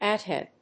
/ˌeˌdiˌeˈtʃdi(米国英語), ˌeɪˌdi:ˌeɪˈtʃdi:(英国英語)/